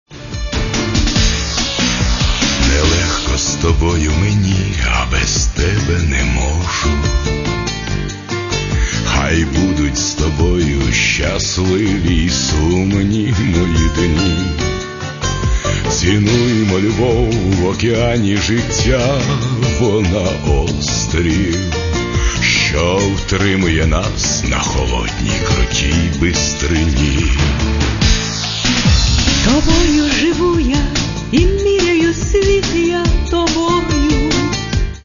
Каталог -> Естрада -> Дуети
ремікс